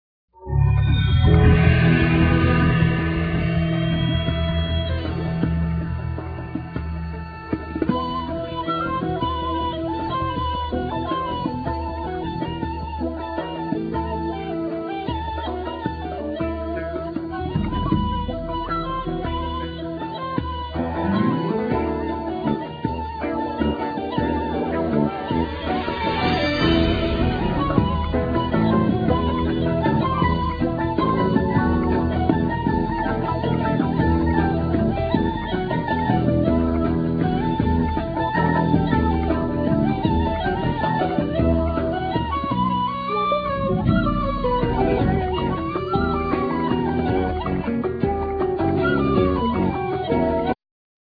Guitar,Guitar Synth,Sample
Shakuhachi
Violin
Piano
Dumbek
Bass
Gaida,Kaval
Flute,Soprano+Alto+Tenor+Baritone Saxophone
Flugelhorn,Trumpet
Trombone
Tuba
Gakokoe(Bell)